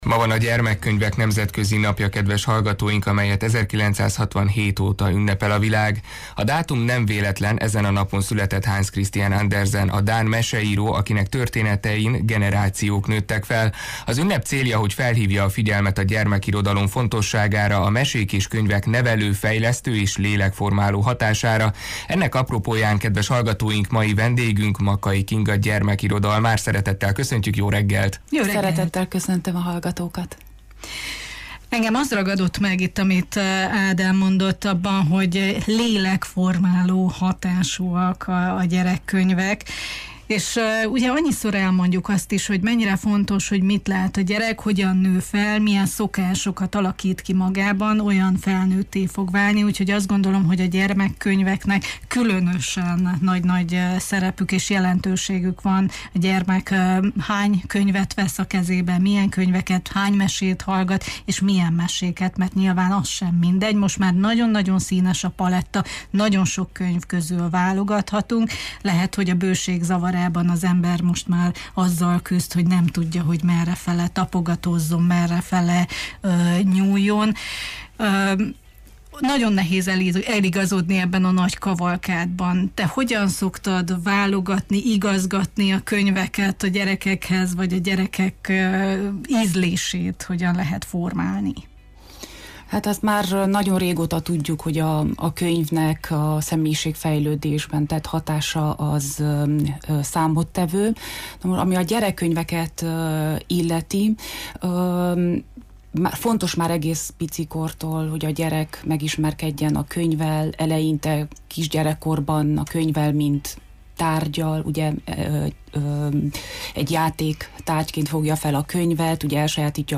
gyermekirodalmár volt a vendégünk: